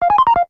lowcharge.ogg